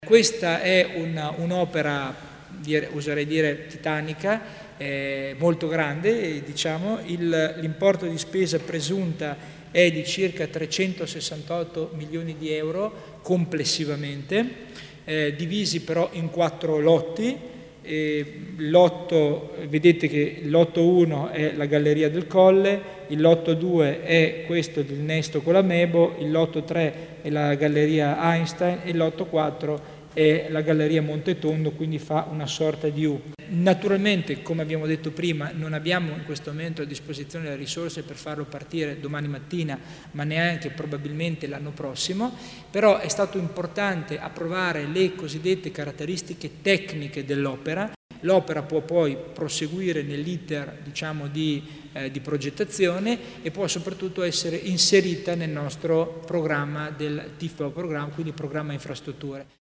Il Vicepresidente Tommasini spiega i dettagli del progetto della circonvallazione di Bolzano